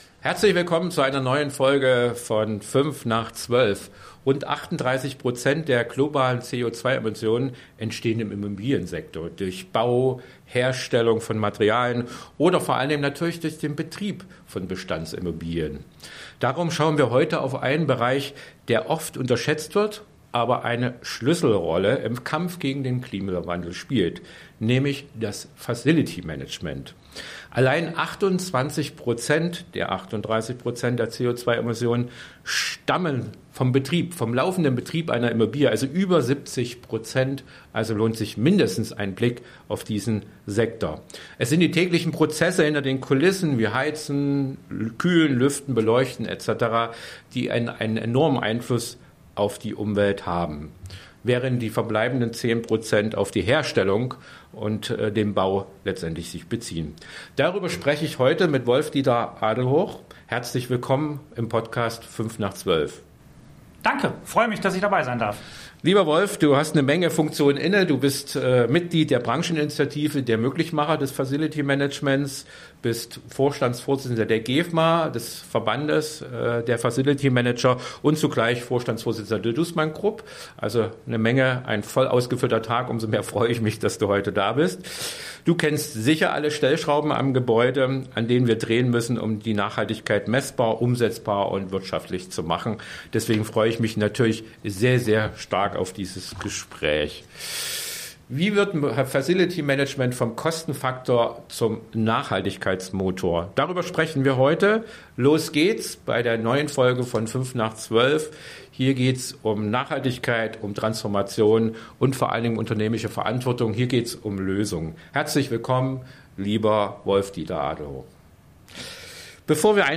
Ein Gespräch über Umsetzung statt Absicht, Chancen statt Pflichten und Menschen, die es möglich machen.